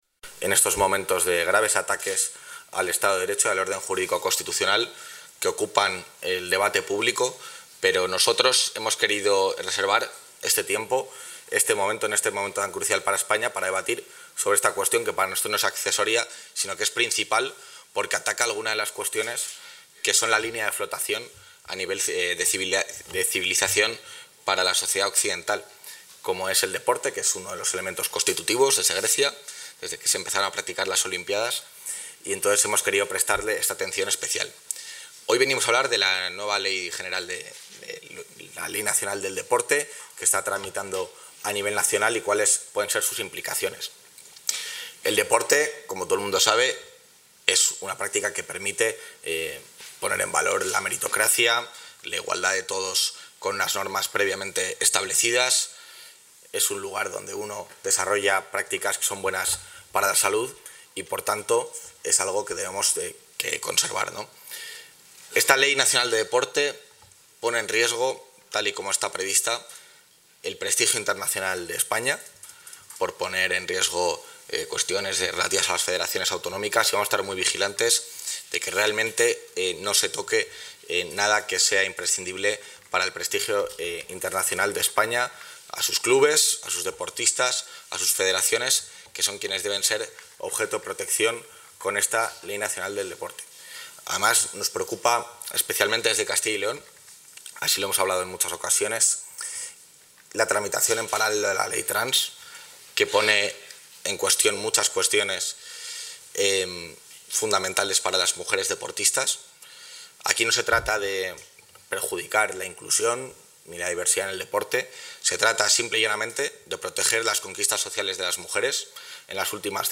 Intervención del vicepresidente.
El vicepresidente de la Junta de Castilla y León, Juan García-Gallardo, ha presidido esta mañana en el salón de actos de las Cortes de Castilla y León la inauguración de la jornada jurídico-deportiva ‘El proyecto de ley nacional del deporte y su impacto en Castilla y León’.